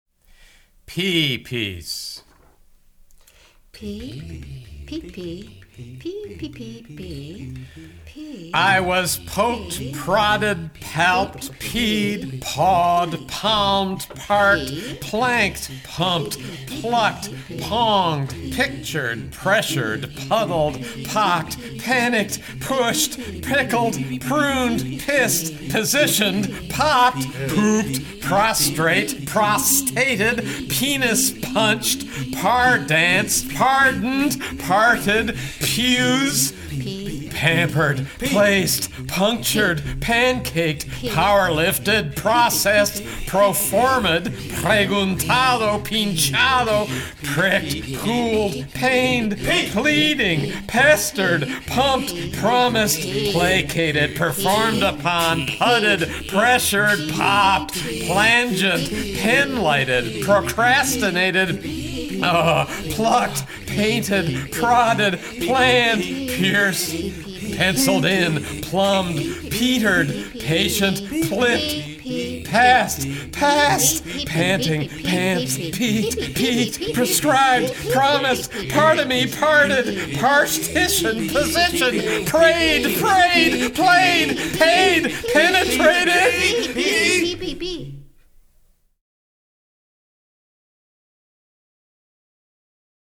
hear the Consort read the visual poem (1.8 megs)